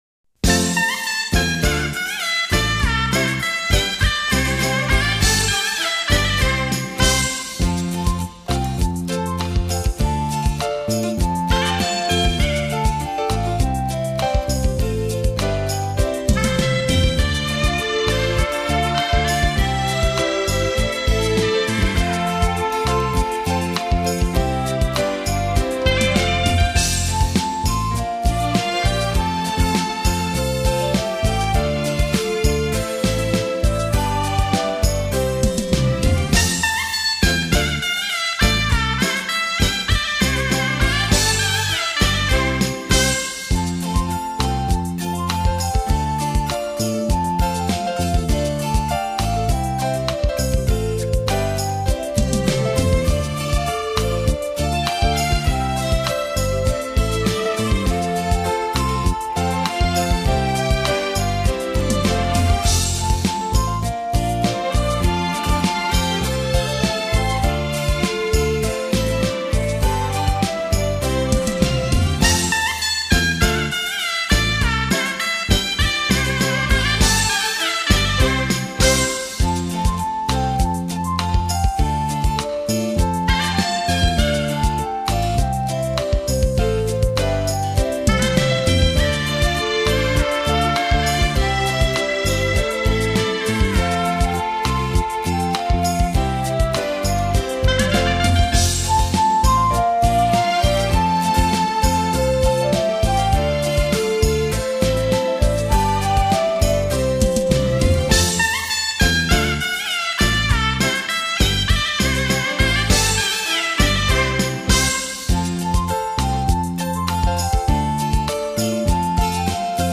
（伴奏）